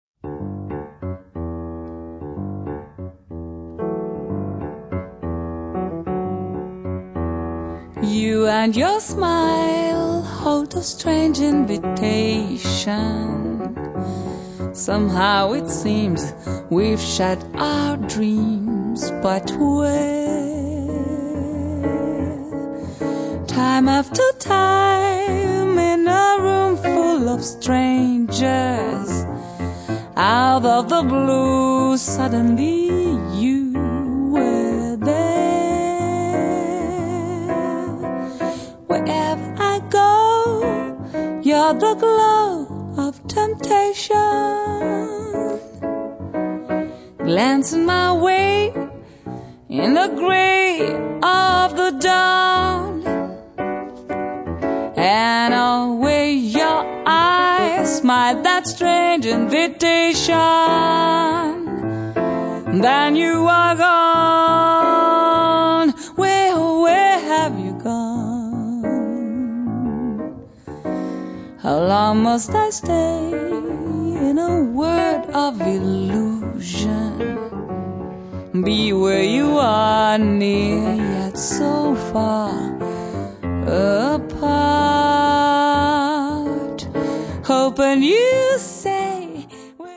La bella e morbida voce